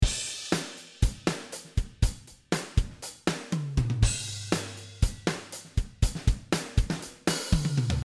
使用している音源は、Superior Drummer 2の標準キットをパラアウトで使っています。
低域をカットした、スッキリしたEQ
ドラム全体
ローをカットする事で、だいぶスッキリとした音になりました。
アンビエンス感はありつつも、先ほどの音とは全く違う方向性になっている事がおわかりいただけるかと思います。